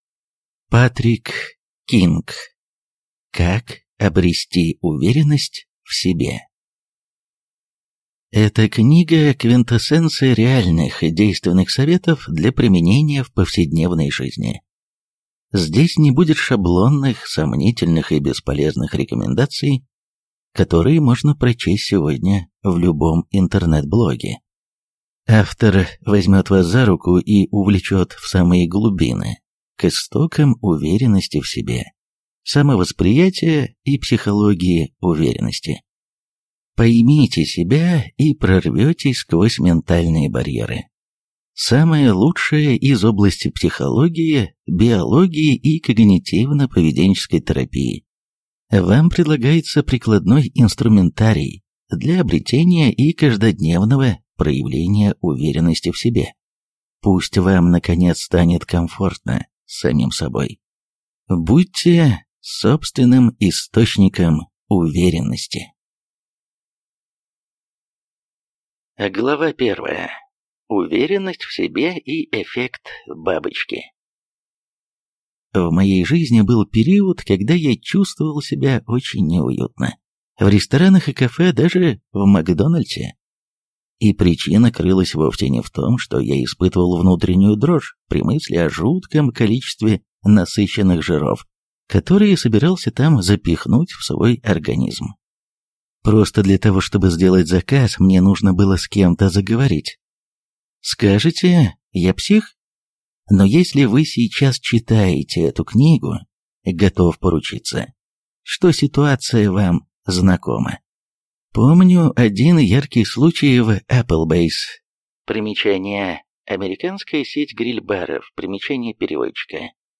Аудиокнига Как обрести уверенность в себе | Библиотека аудиокниг